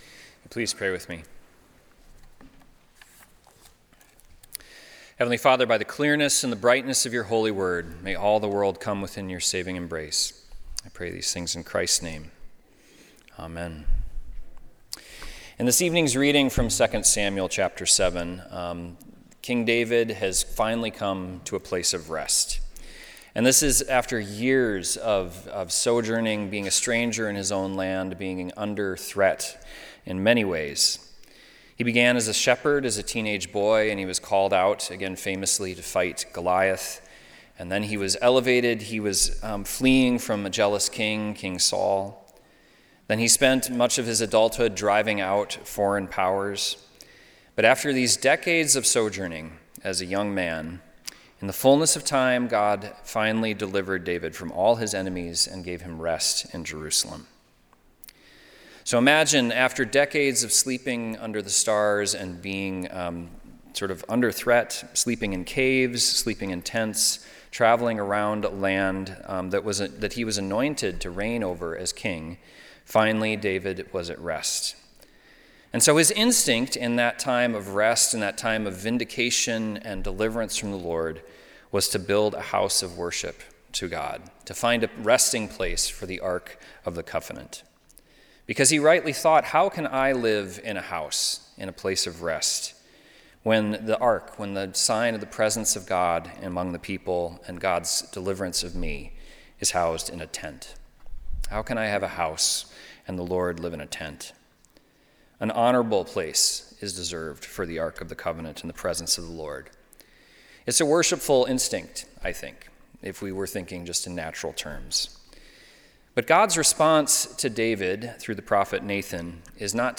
Homily from 2 Samuel 7
Christmas Eve – Dec. 24, 2025